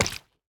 Minecraft Version Minecraft Version snapshot Latest Release | Latest Snapshot snapshot / assets / minecraft / sounds / block / honeyblock / break5.ogg Compare With Compare With Latest Release | Latest Snapshot